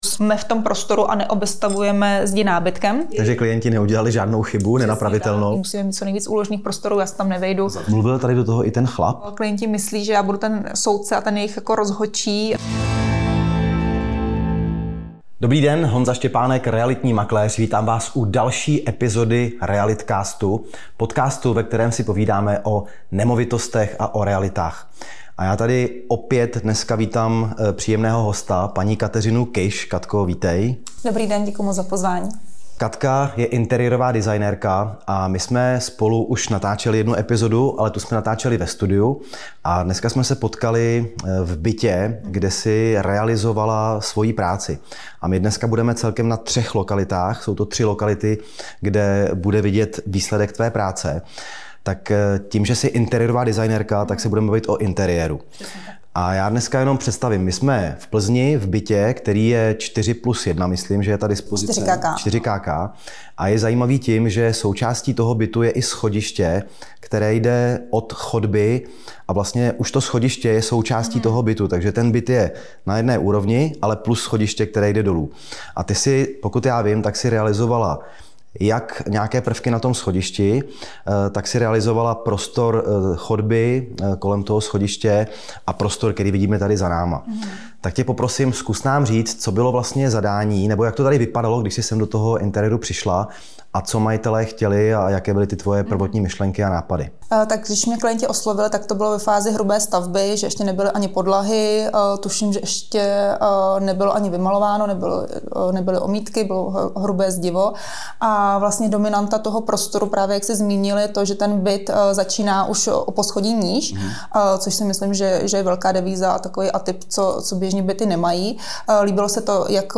tentokrát už ne ve studiu, ale přímo v hotových interiérech, které navrhla. Společně navštívili tři odlišné prostory, kde každý detail hraje roli: od městského bytu přes rodinný dům až po přestavbu bývalého kina.